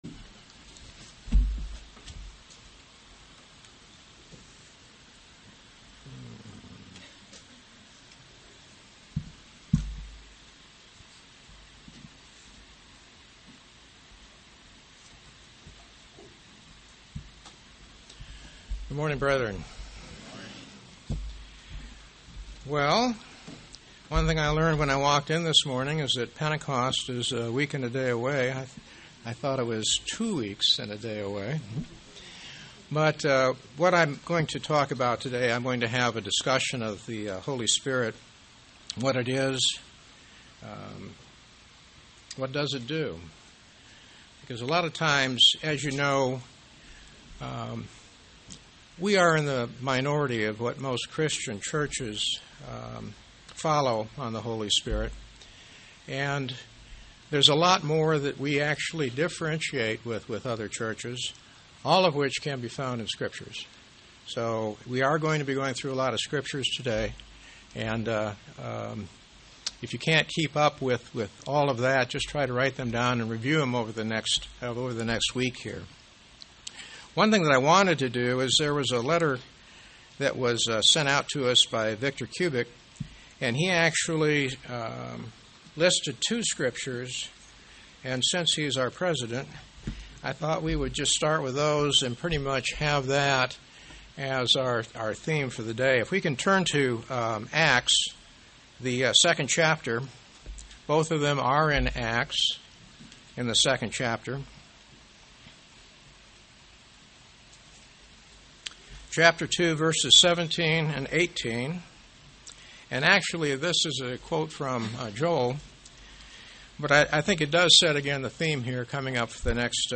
Given in St. Petersburg, FL
UCG Sermon Studying the bible?